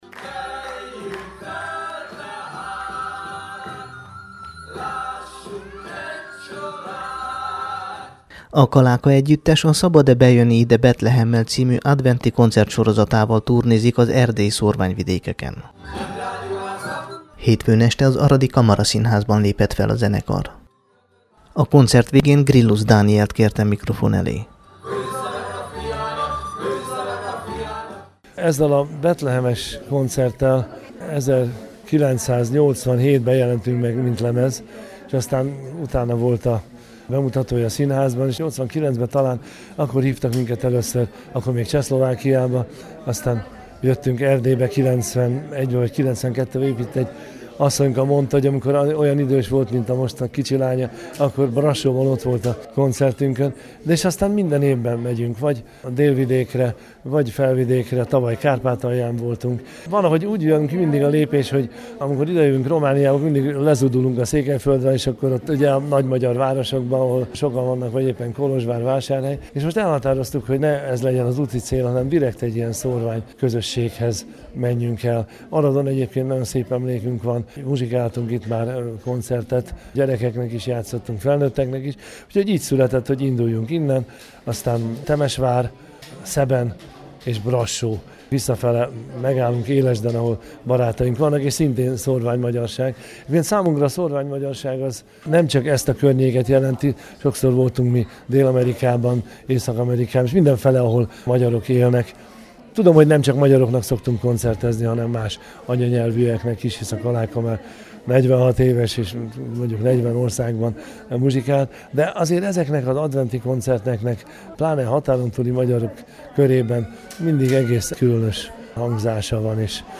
kalaka_es_fono-koncert.mp3